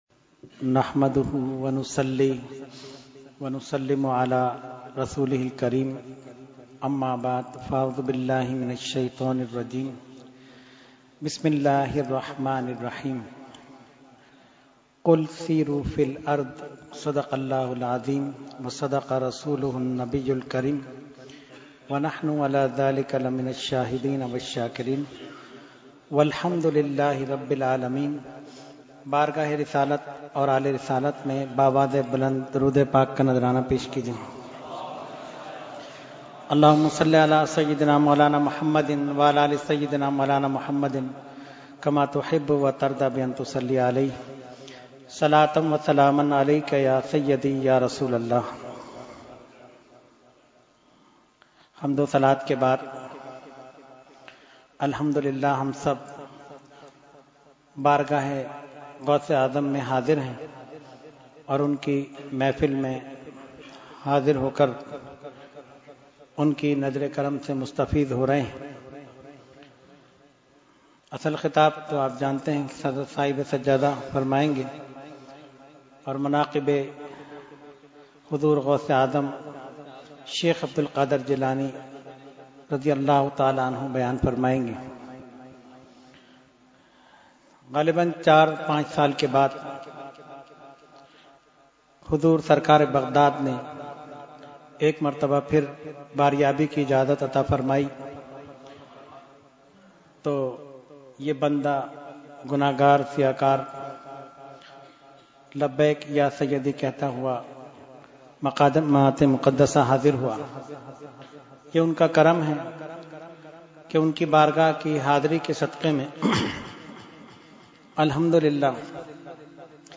Category : Speech | Language : UrduEvent : 11veen Shareef 2018